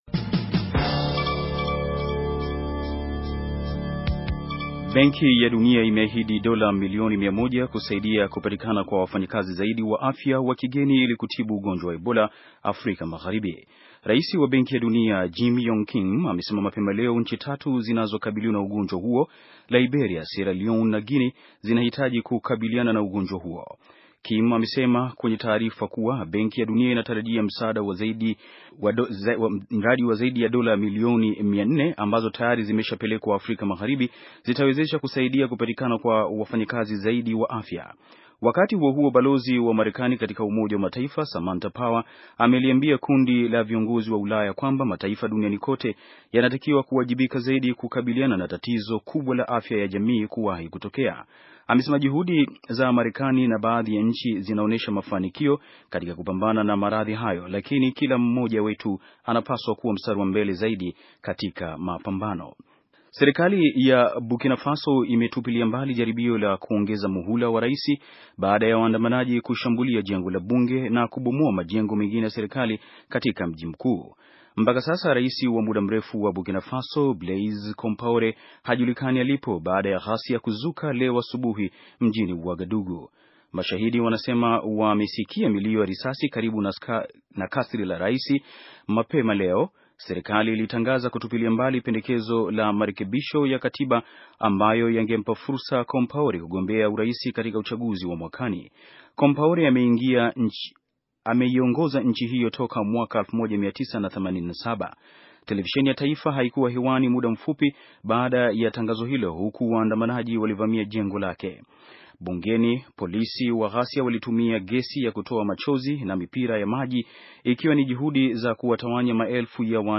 Taarifa ya habari - 6:40